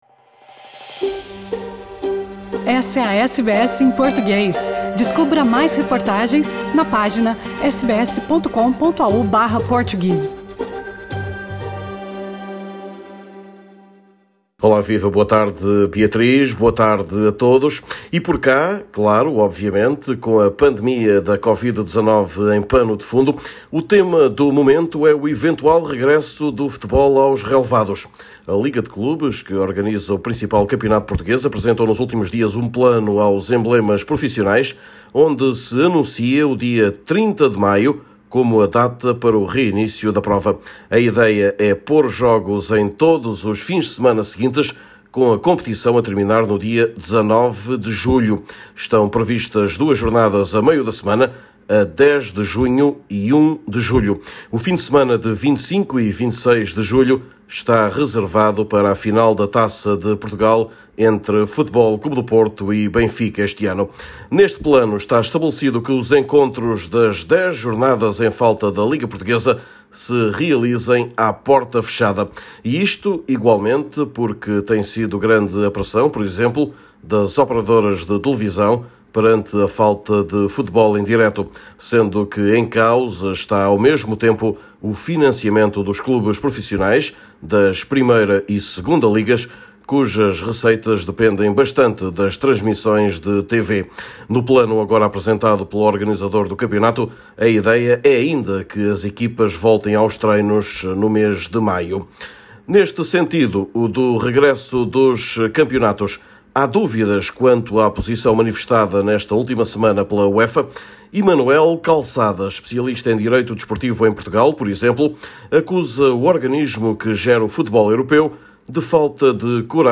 Neste boletim semanal vamos falar ainda da hipótese de redução dos vencimentos dos jogadores, que já é fato em Espanha ou Itália mas que não foi oficializada no futebol luso.